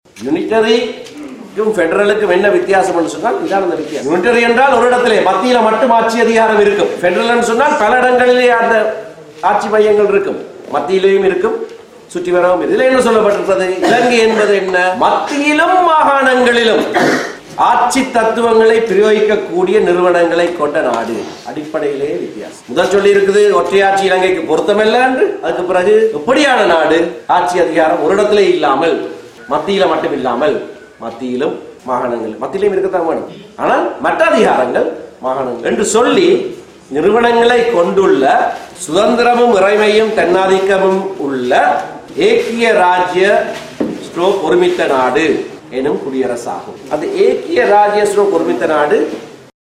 கட்சியின் புதுக்குடியிருப்பு நிர்வாகிகளை தெரிவு செய்யும் கூட்டம் நேற்று நடைபெற்ற போது, நாடாளுமன்ற உறுப்பினர் எம்.ஏ.சுமந்திரன் இதனைத் தெரிவித்துள்ளார்.